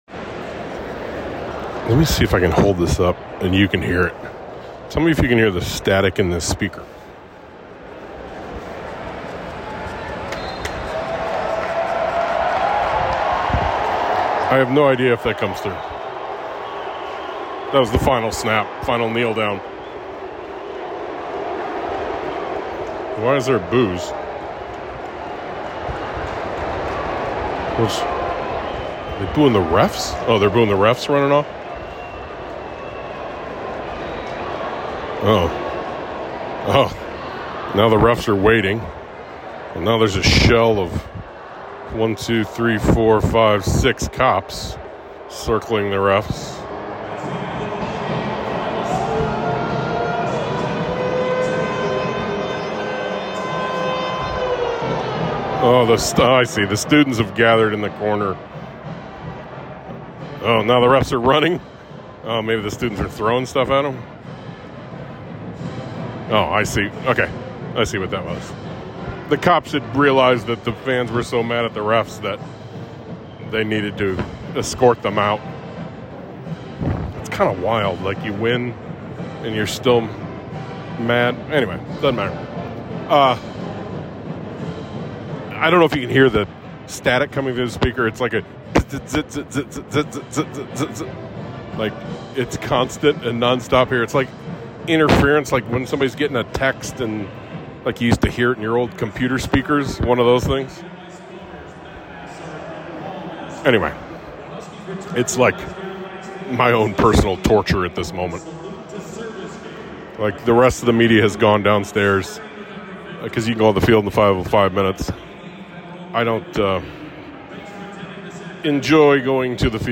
It bounces around a lot. But I stuck my phone up to my face and recorded 20 minutes after a terrible loss.